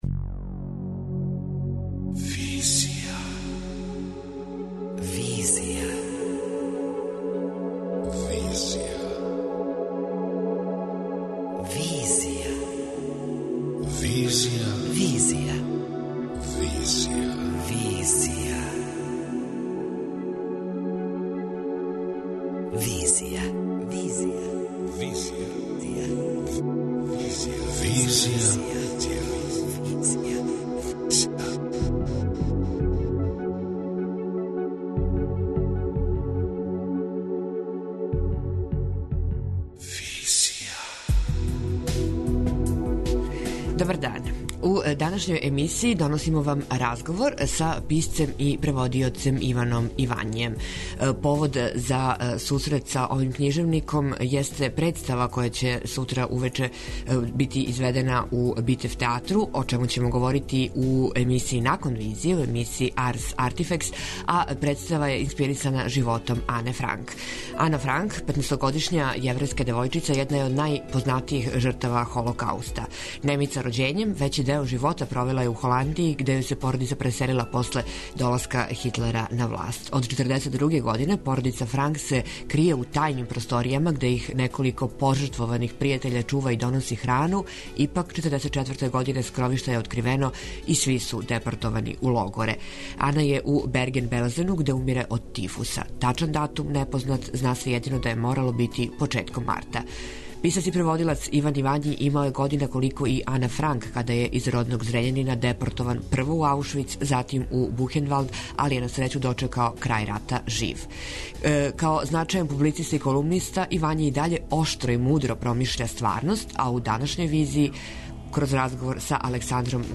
преузми : 23.90 MB Визија Autor: Београд 202 Социо-културолошки магазин, који прати савремене друштвене феномене.